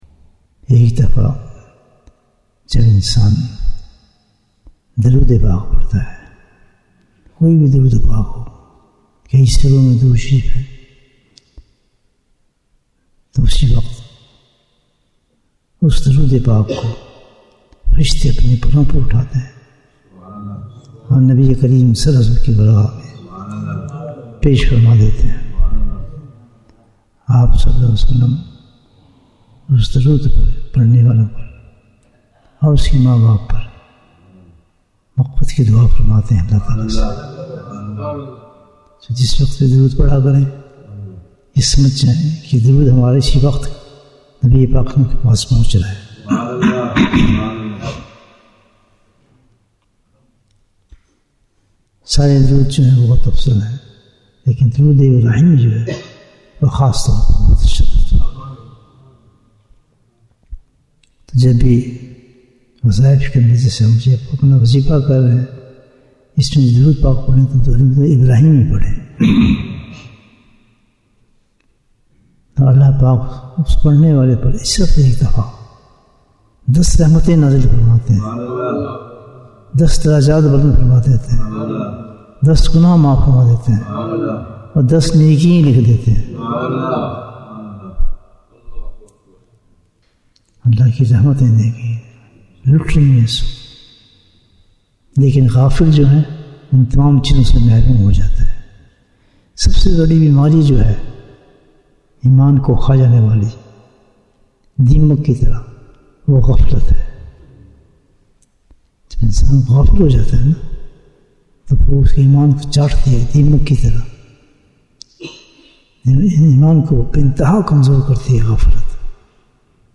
Bayan, 9 minutes